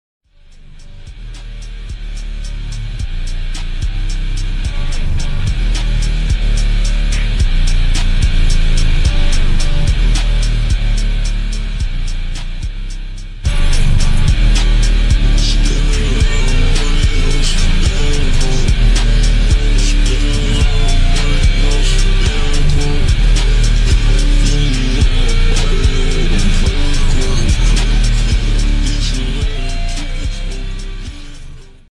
Slowed + Curve